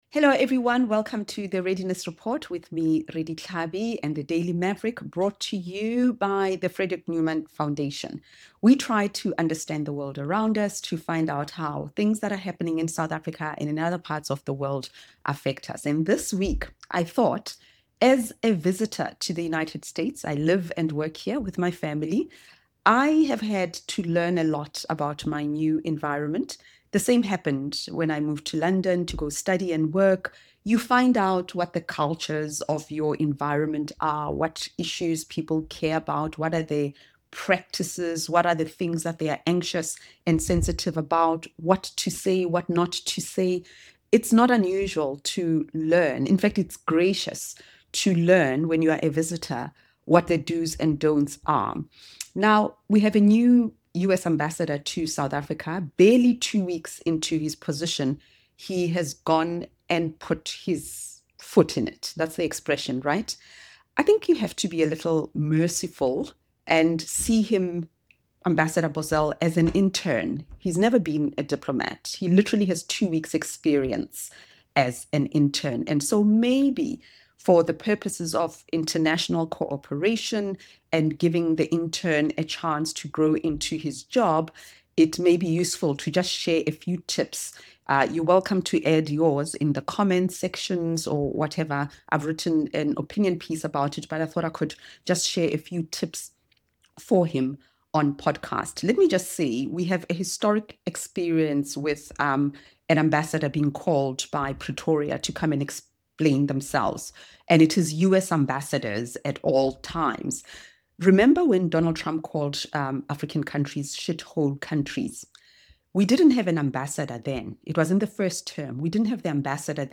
In this solo episode of The Readiness Report, Redi Tlhabi discusses newly appointed US Ambassador to South Africa, Leo Brent Bozell III, and the recent rebuke from Foreign Minister Ronald Lamola. Drawing on her own experience of living abroad, from the United States to London, she offers four “tips” on what it means to enter a new society as a guest, why understanding local sensitivities matters, and how diplomacy can unravel when those lessons are ignored.